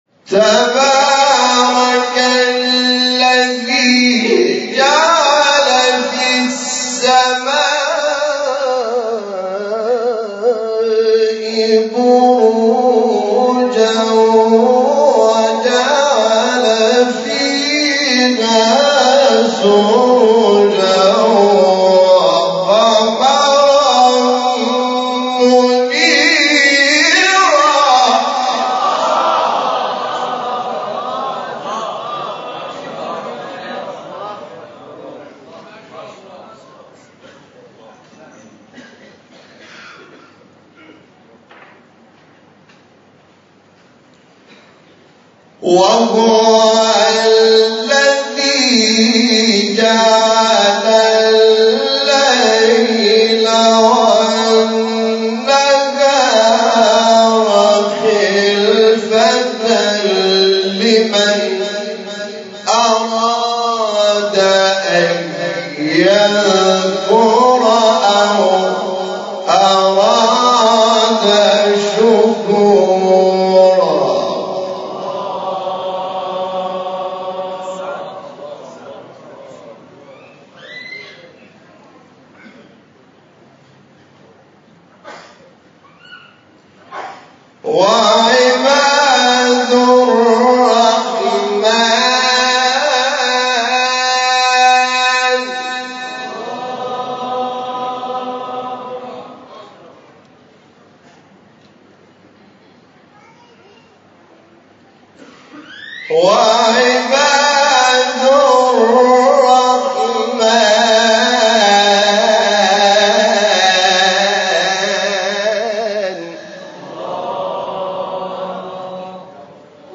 آیه 61-62 فرقان استاد حامد شاکرنژاد مقام بیات | نغمات قرآن | دانلود تلاوت قرآن